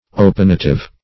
Search Result for " opinative" : The Collaborative International Dictionary of English v.0.48: Opinative \O*pin"a*tive\, a. Obstinate in holding opinions; opinionated.